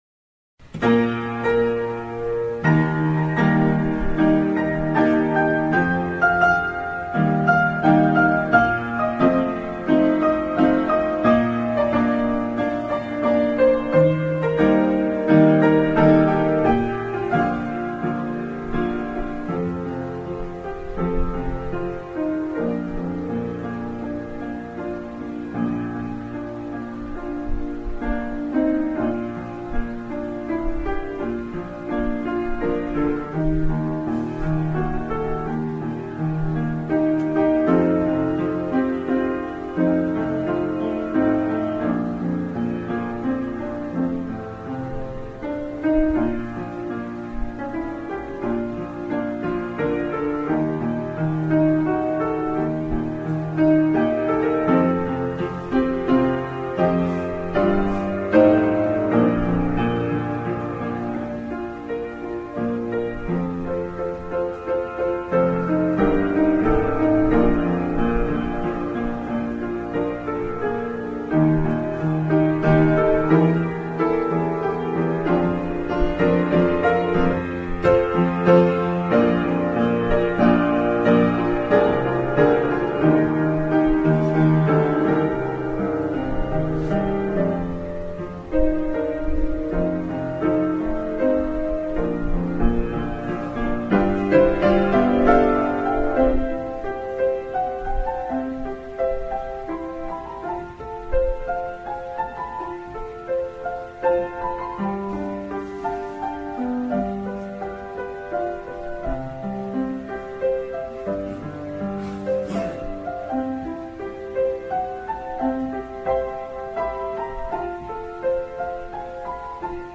피아노연주